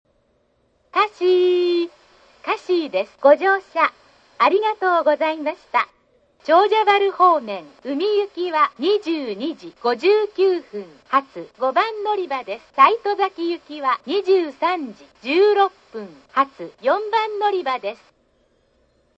到着＋のりかえ放送